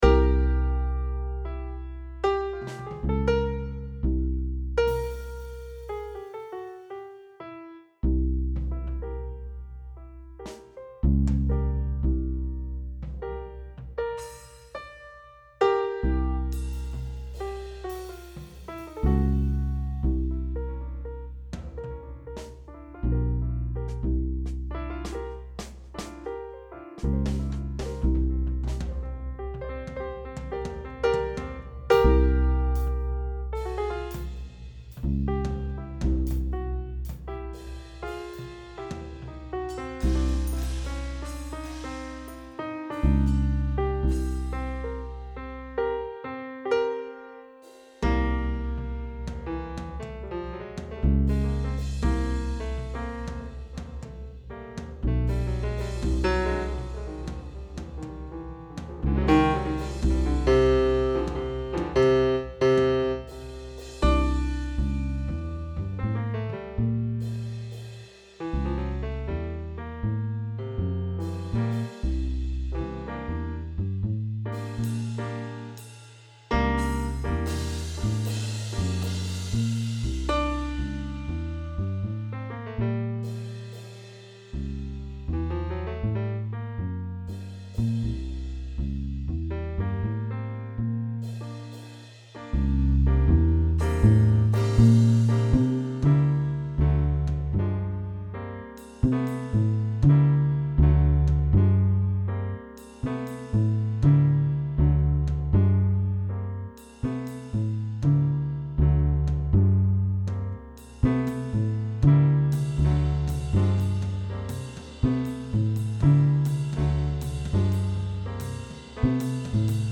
experimental electronic audio works